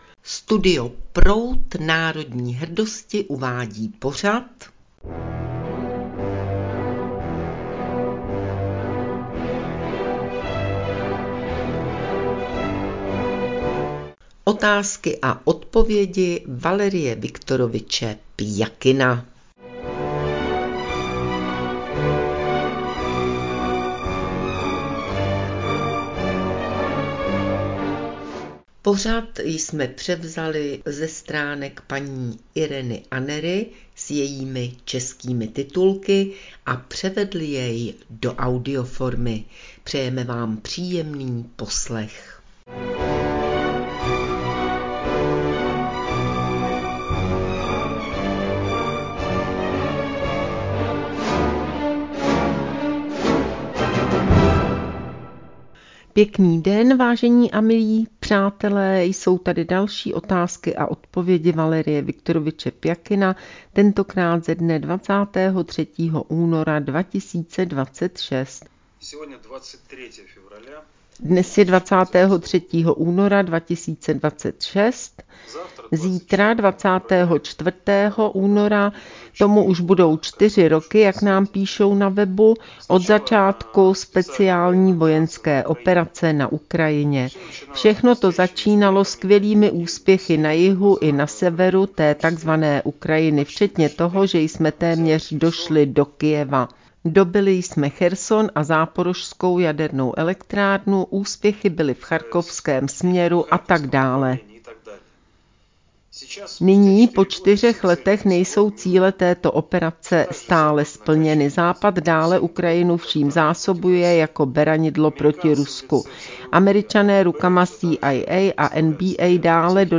s českým dabingom